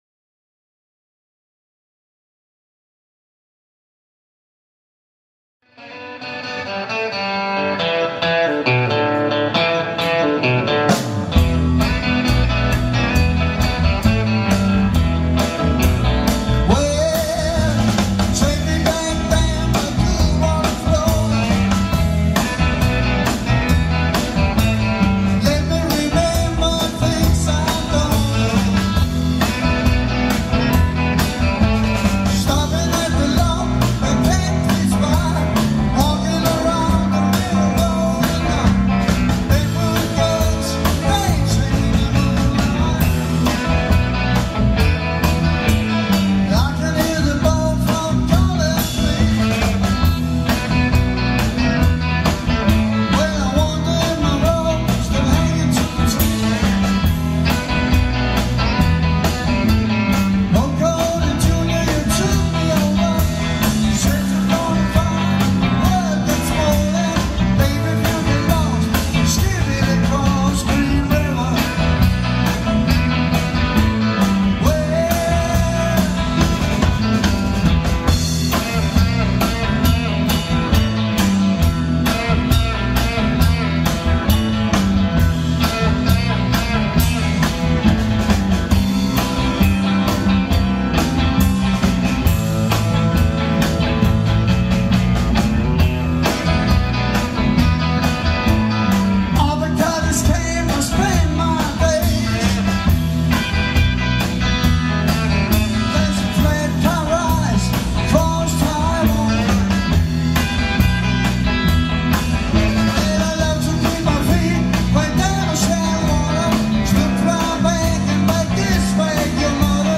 LIVE I KBH.
VOCAL
GUITAR & KOR
BASS
LEAD GUITAR
DRUMS